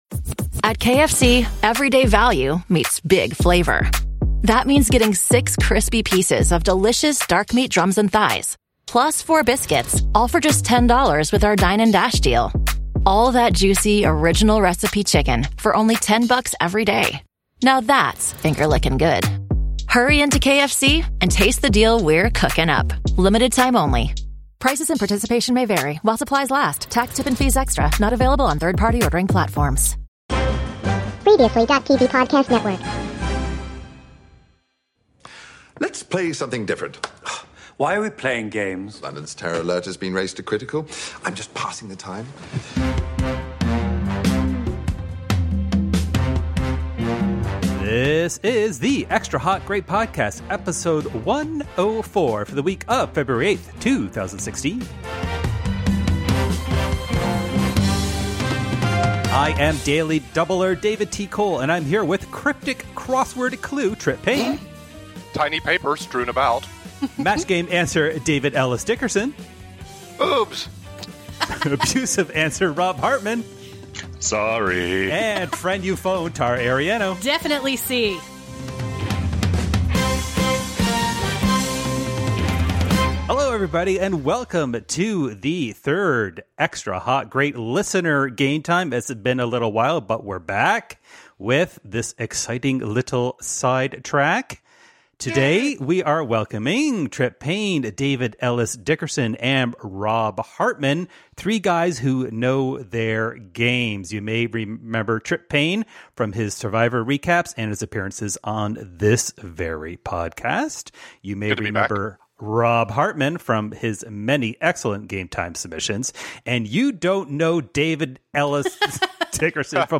TV show theme quiz. Oh, the clips are only one and three seconds long.